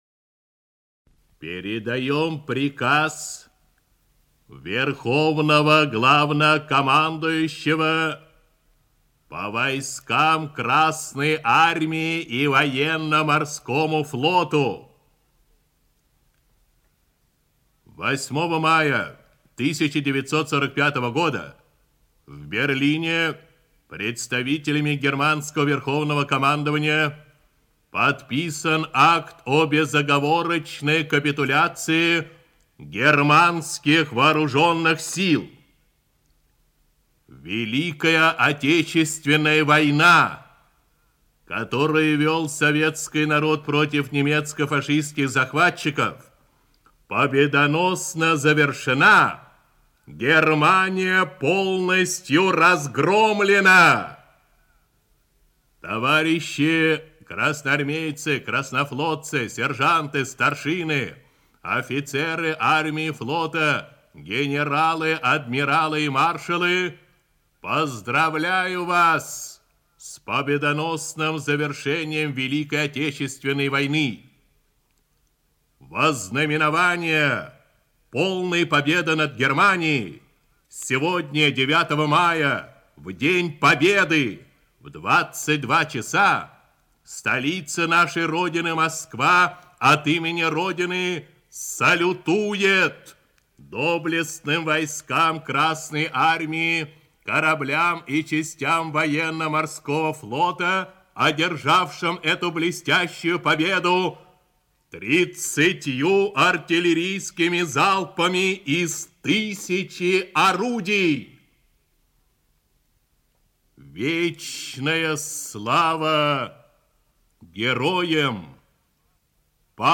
Текст читает диктор Всесоюзного радио, народный артист РСФСР и СССР Левитан Юрий Борисович